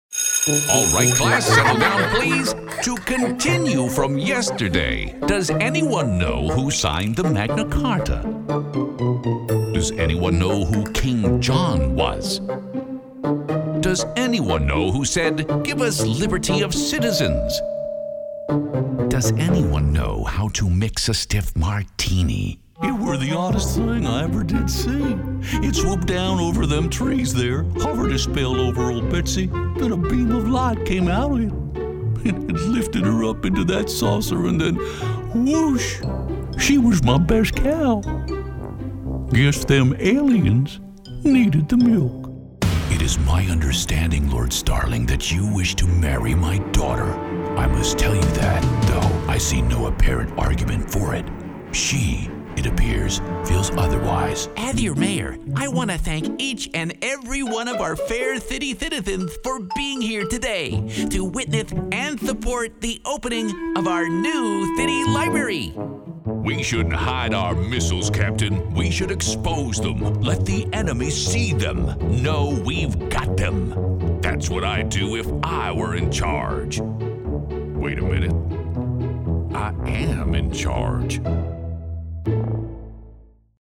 VOICE OVER DEMOS
NEW! Animation